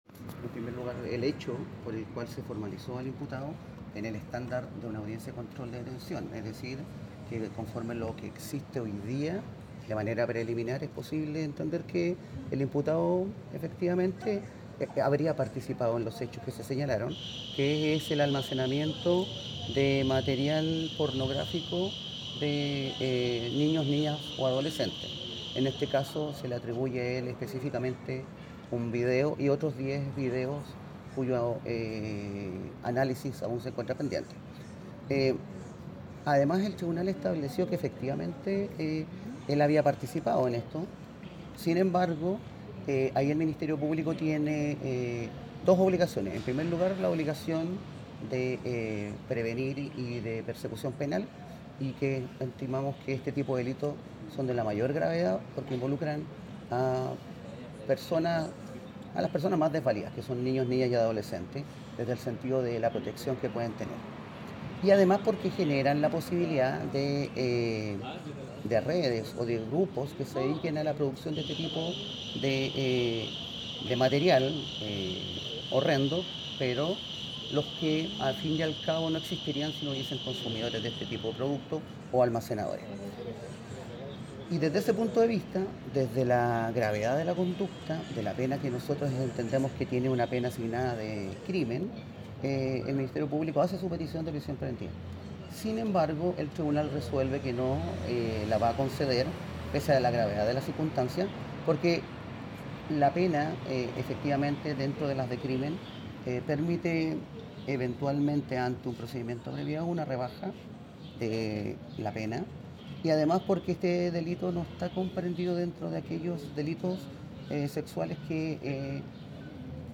Audio Fiscal de Valparaíso, Maximiliano Krause